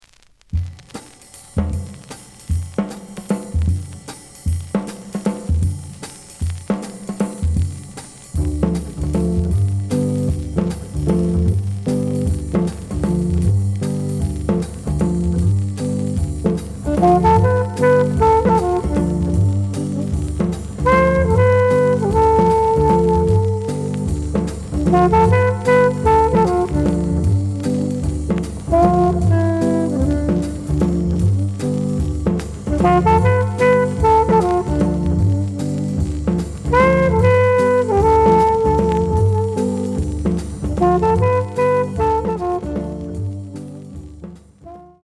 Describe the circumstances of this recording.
The audio sample is recorded from the actual item. ●Format: 7 inch